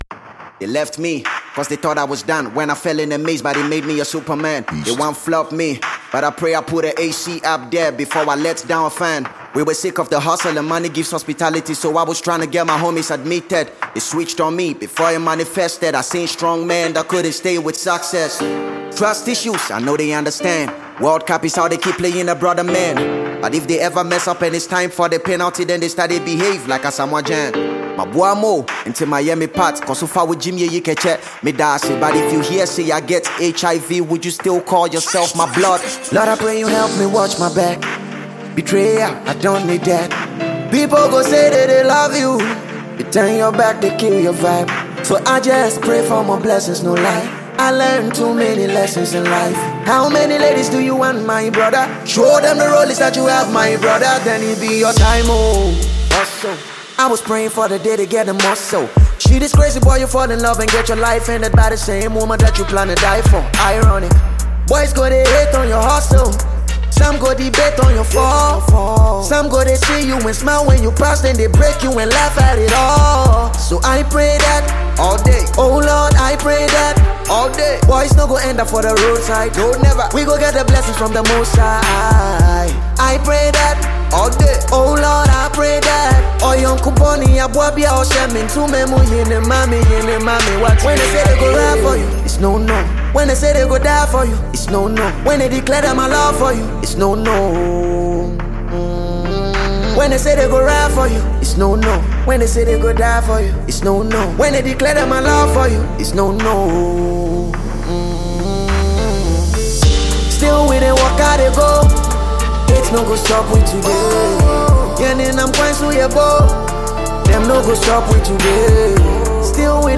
Ghana MusicMusic
energetic, talented and awesome rapper